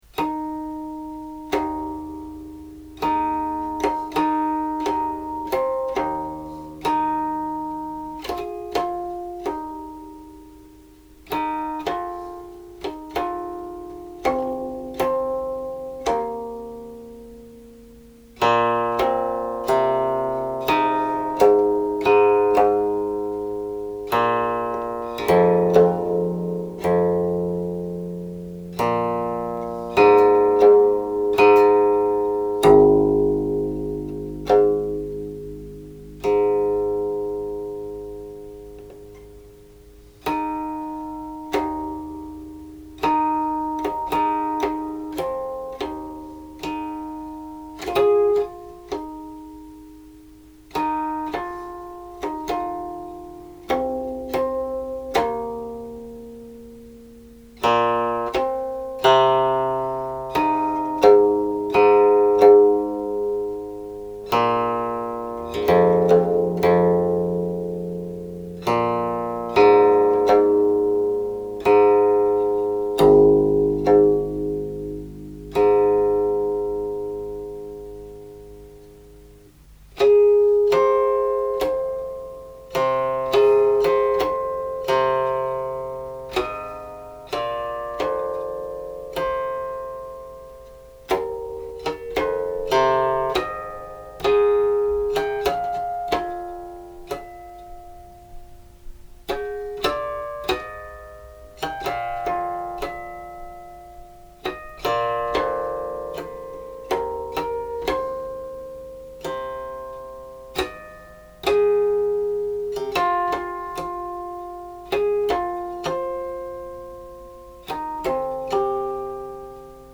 listen 聽錄音) is influenced by the lyrics, included on this transcription;
The addition of the lyrics (中文) has made this version quite a bit slower that the one on my CD (聽錄音 listen with that transcription)
For Huangzhong mode, slacken 1st, tighten 5th strings each a half step.